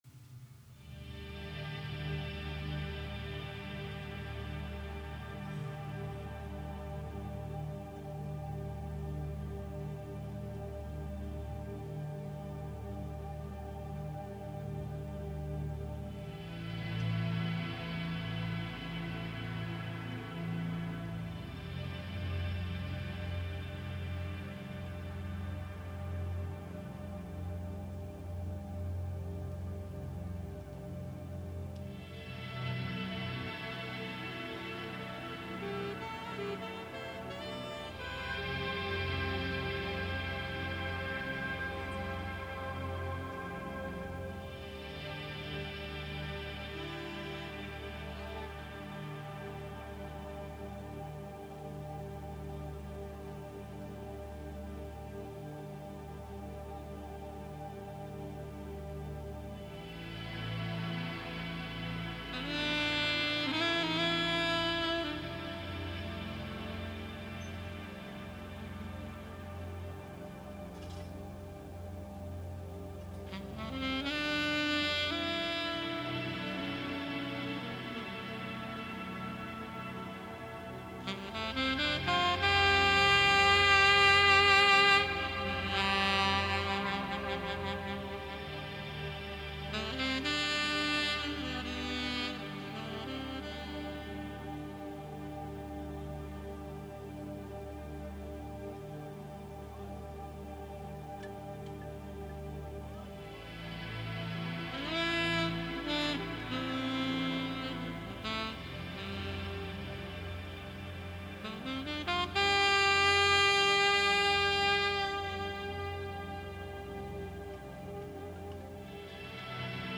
Plaat on lindistatud augustiöösel mittefestivalil